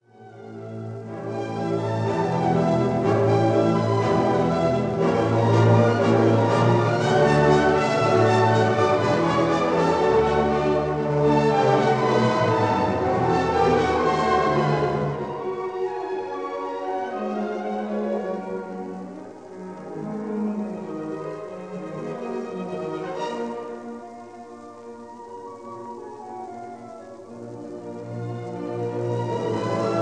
in Londons Walthamstow Assembly Hall